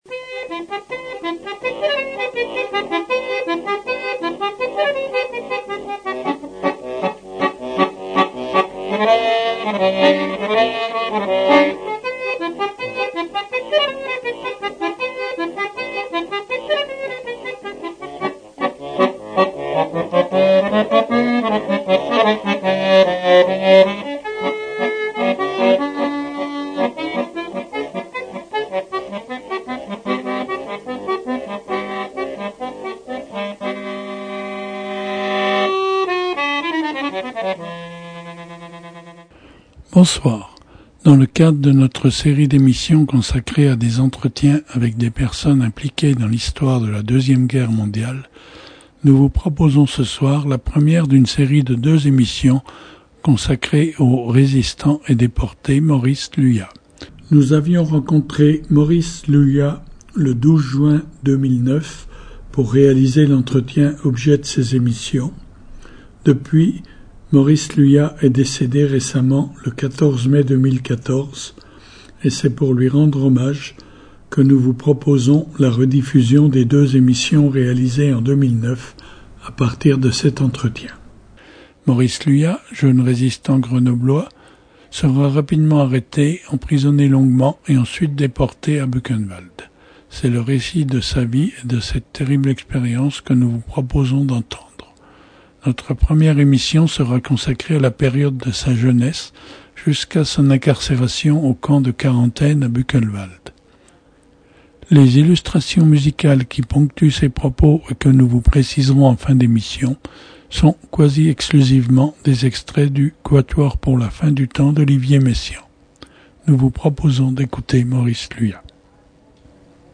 dans le cadre de notre série d’émissions consacrée à des entretiens avec des personnes impliquées dans la deuxième guerre mondiale